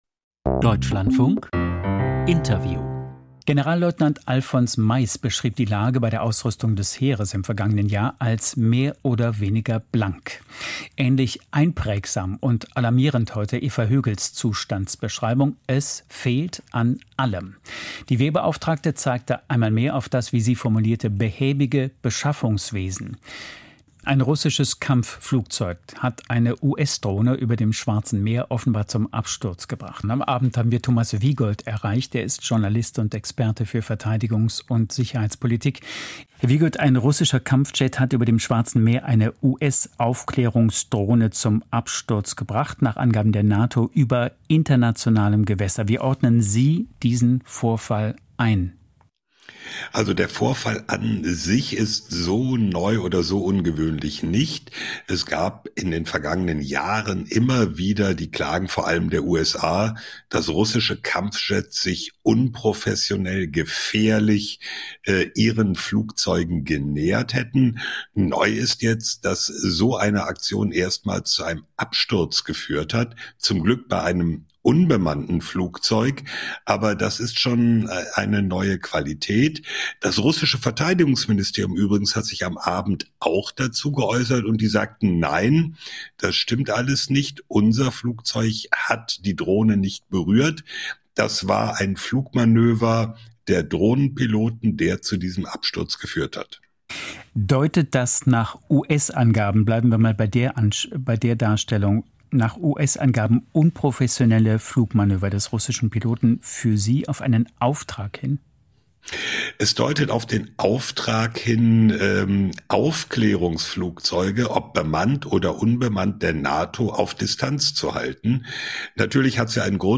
Interview/Wehrbericht